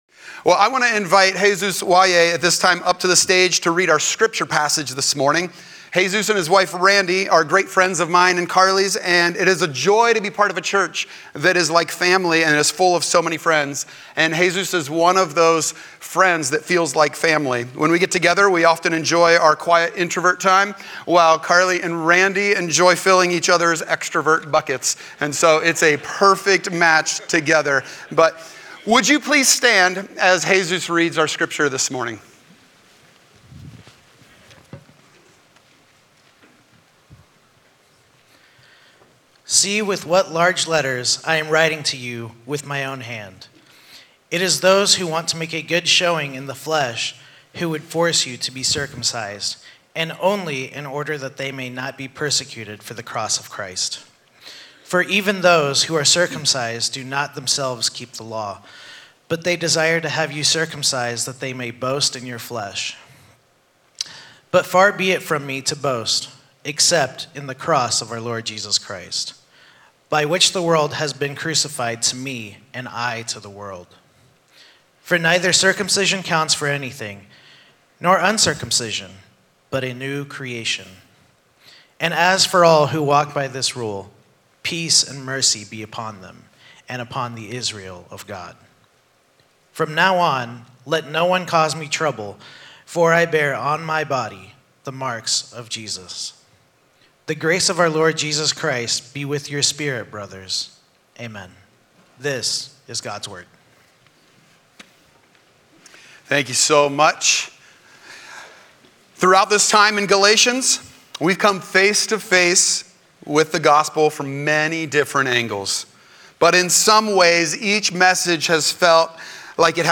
A sermon from the series "Freedom in the Gospel."